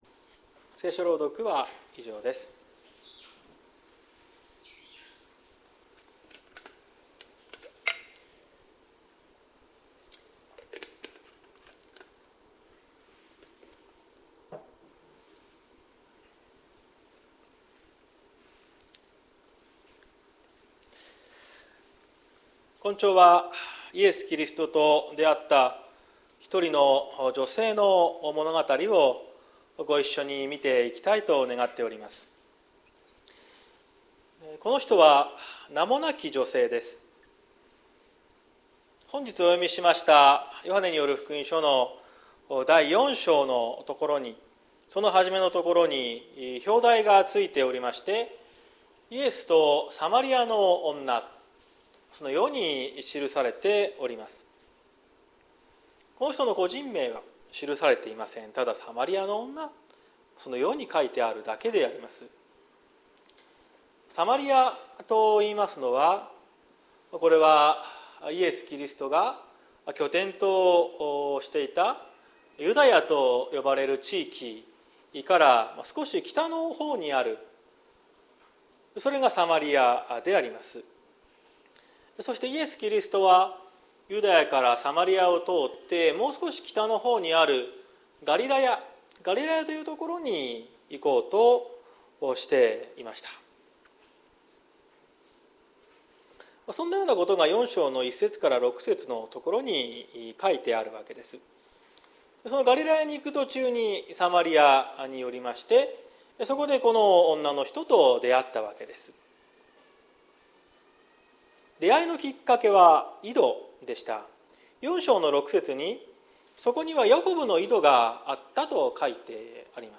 2023年03月26日朝の礼拝「渇くことのない水」綱島教会
説教アーカイブ。